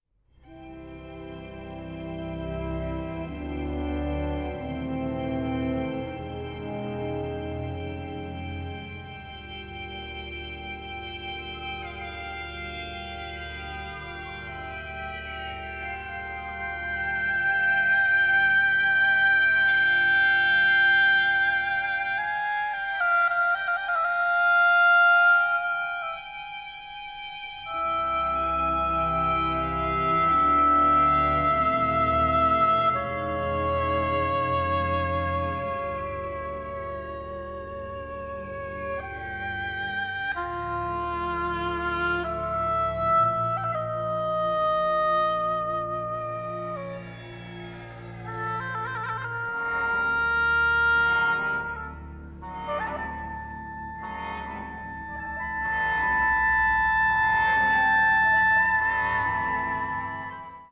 Stereo/Multichannel